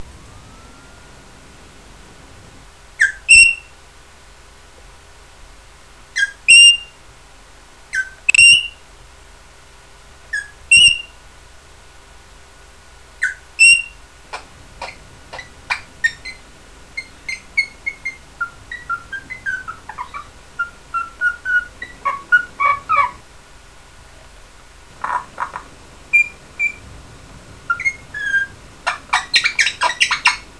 This page is devoted to Makro, my Crimson Rosella (also known as Red Pennant, but lets not go into why) She also goes by the name as Macky, Mak and Maki.